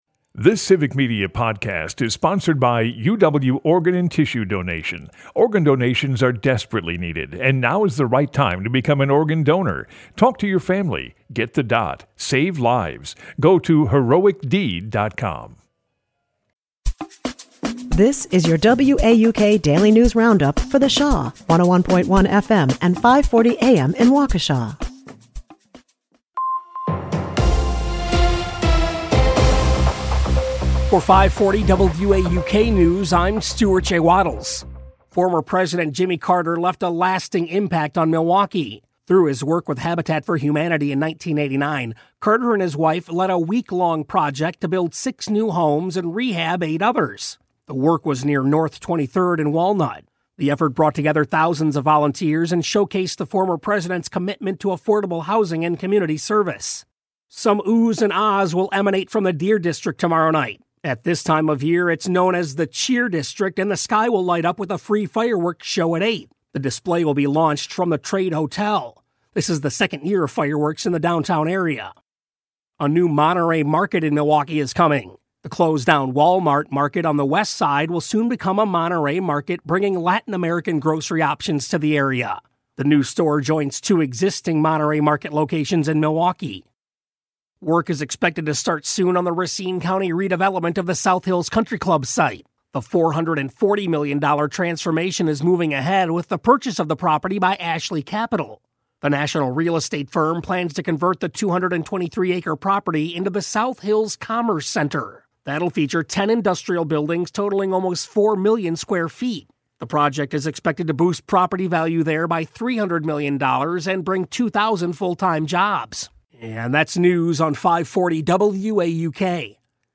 The WAUK Daily News Roundup has your state and local news, weather, and sports for Milwaukee, delivered as a podcast every weekday at 9 a.m. Stay on top of your local news and tune in to your community!